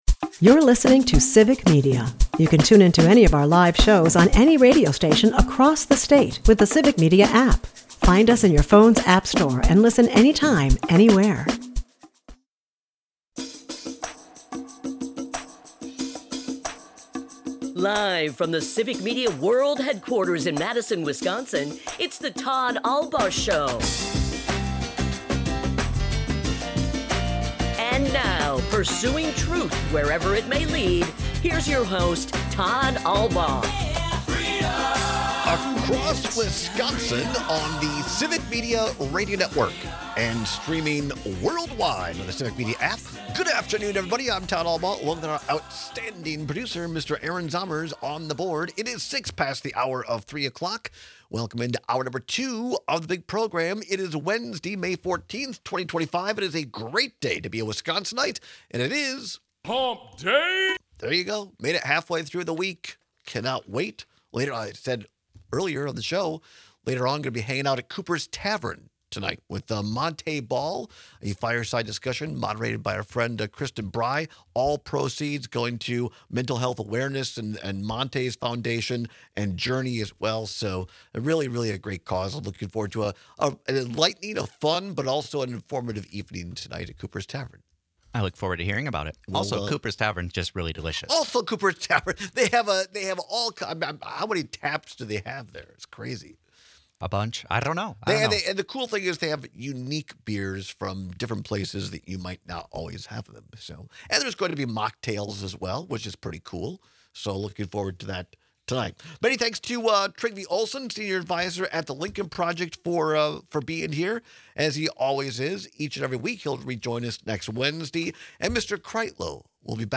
is a part of the Civic Media radio network and airs live Monday through Friday from 2-4 pm across Wisconsin.